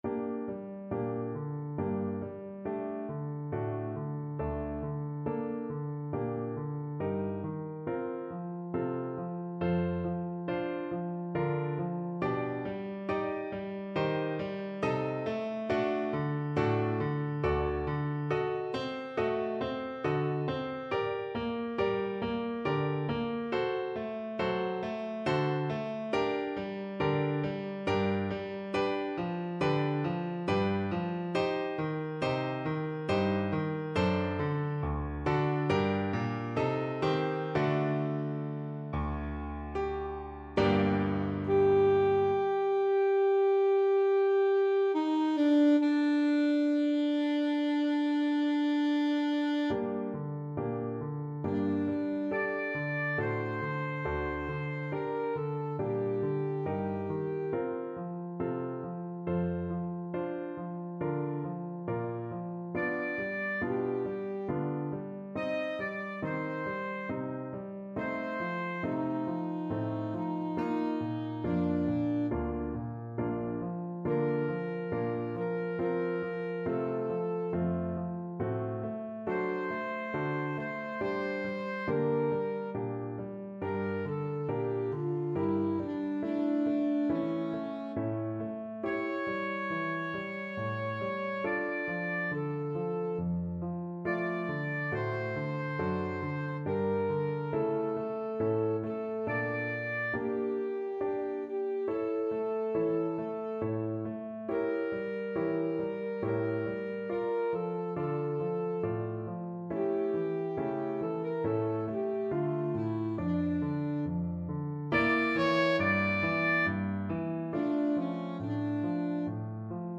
Alto Saxophone version
Alto Saxophone
Andante stretto (=c.69)
3/4 (View more 3/4 Music)
Classical (View more Classical Saxophone Music)